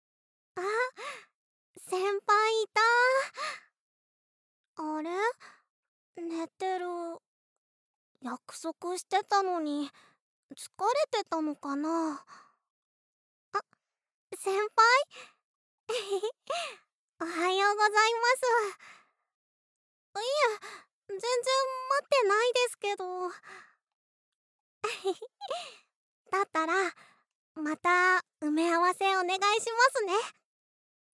ボイスサンプル
おっとり後輩